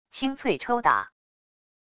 清脆抽打wav音频